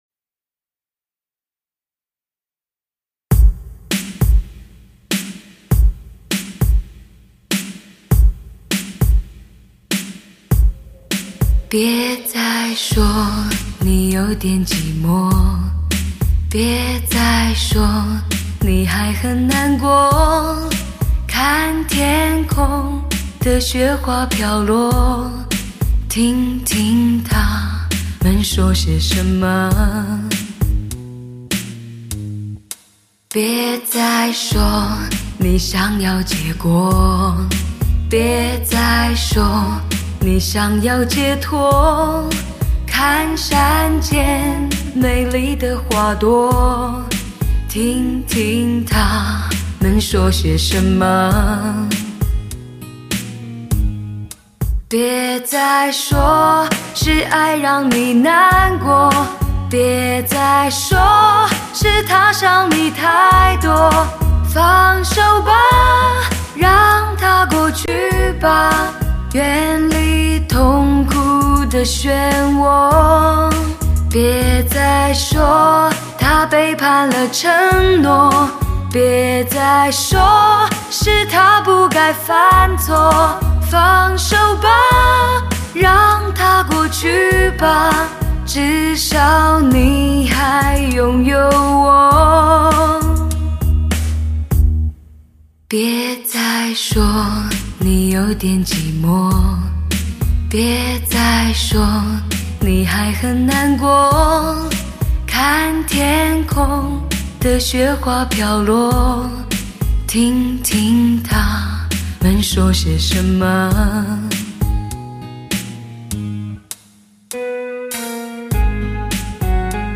唱片类型：汽车音乐
极富视听效果的发烧靓声，德国版HD高密度24bit数码录音，顶级